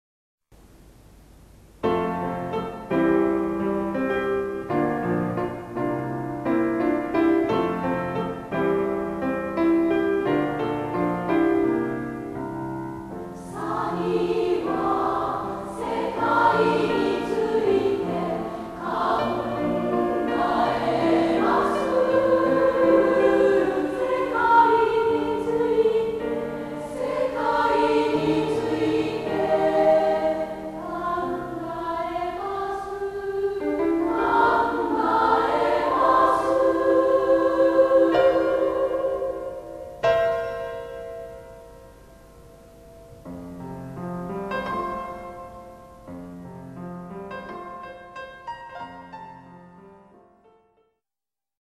楽譜販売中：女声合唱組曲「地雷ではなくをください